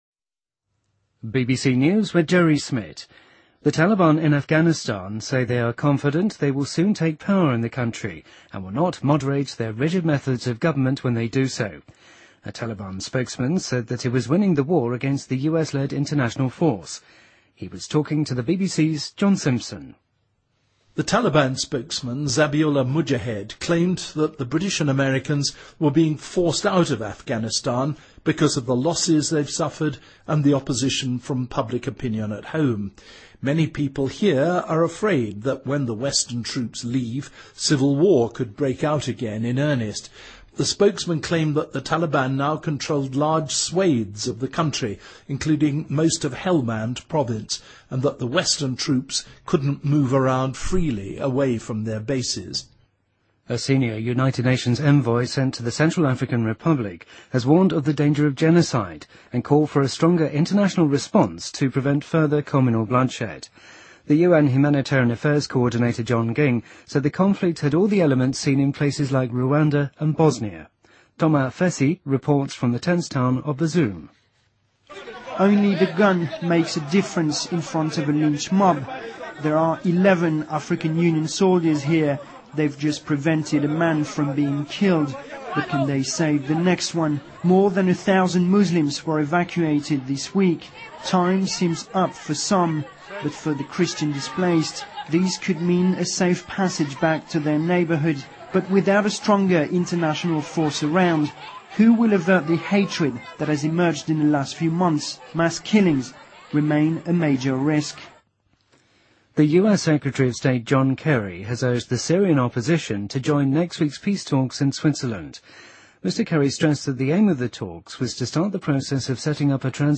BBC news,2014-01-17